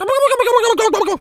turkey_ostrich_gobble_17.wav